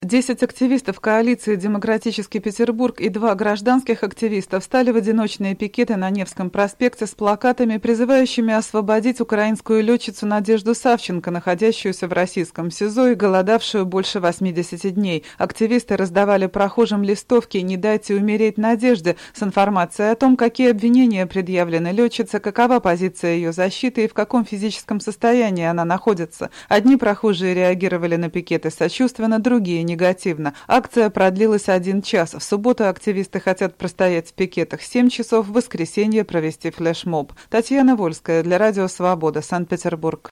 Из Петербурга передает корреспондент